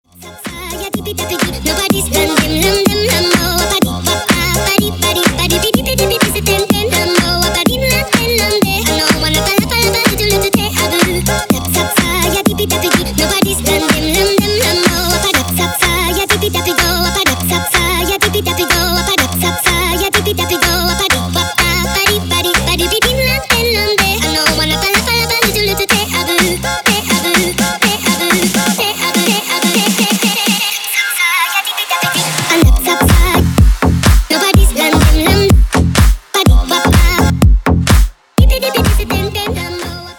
• Качество: 320, Stereo
веселые
Electronic
progressive house
детский голос
Интересная вариация финской польки